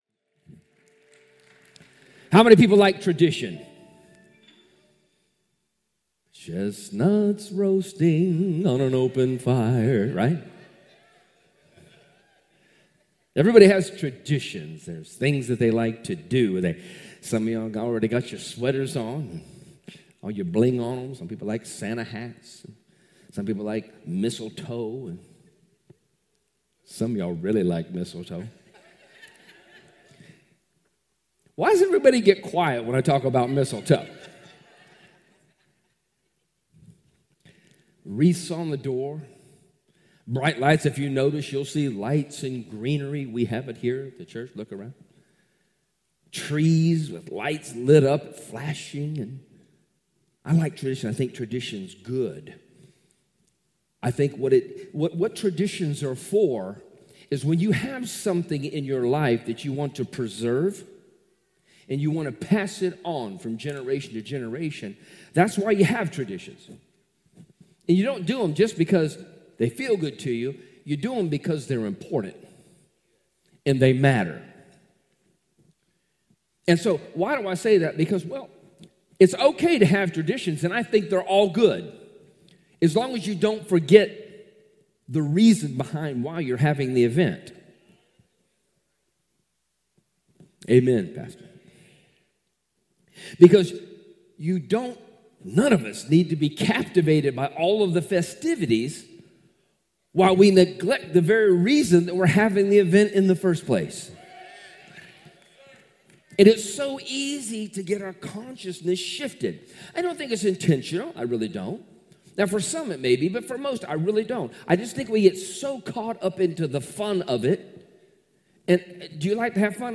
Sunday and Wednesday sermons from Glory To Him Church in Ozark, AL.